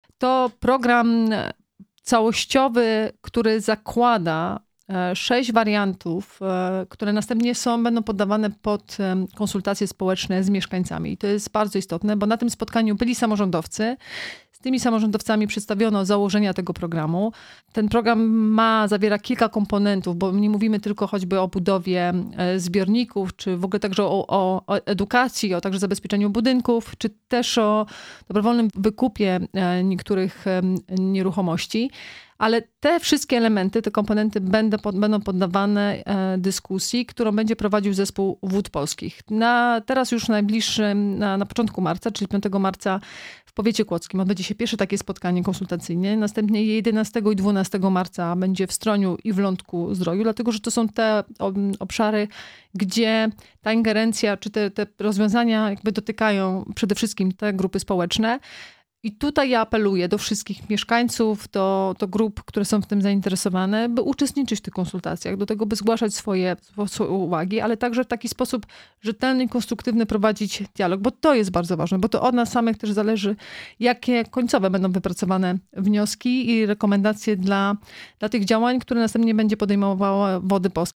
Podkreśliła także znaczenie planowania długofalowego w zakresie ochrony przeciwpowodziowej gmin, a także zaapelowała do mieszkańców terenów popowodziowych do udziału w konsultacjach: